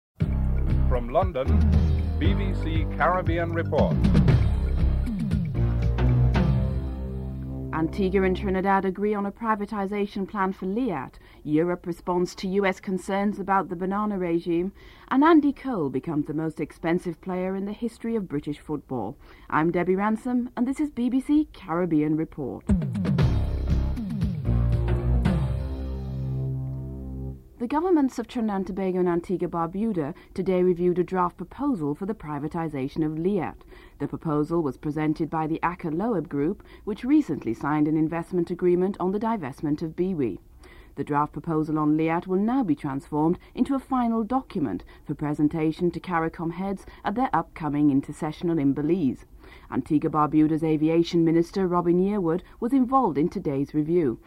The Antigua/Barbuda Aviation Minister Robin Yearwood was interviewed on the pending divestment.
3. Interview with Robin Yearwood on the privatization of LIAT (00:59-04:22)
7. Interview with Clement Rohee on the revived talks and what this means for CARICOM (06:56-10:19)